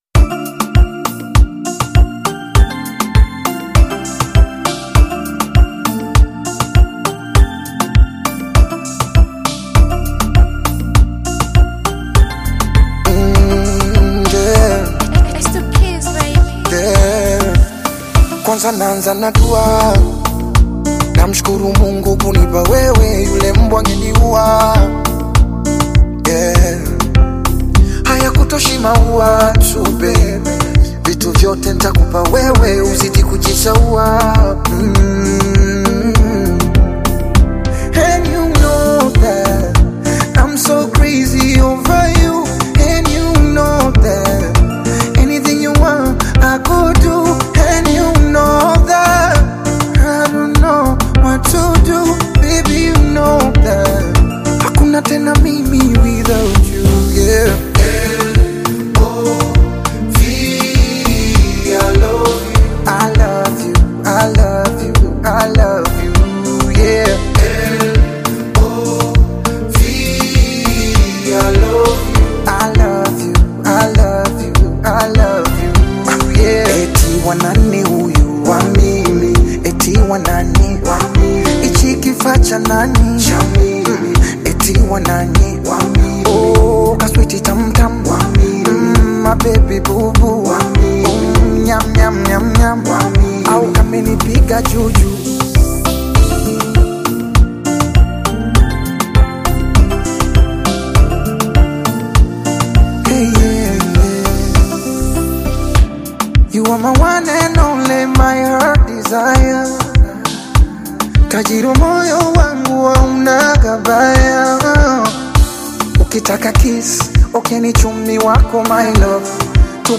R&B with African beats